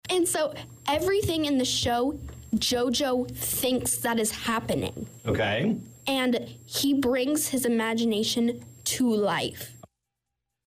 in the D102 studios